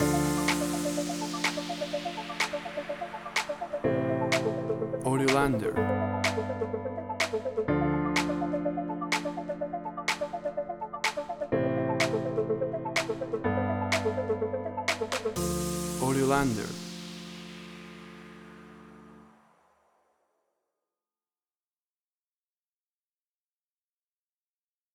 WAV Sample Rate: 16-Bit stereo, 44.1 kHz
Tempo (BPM): 124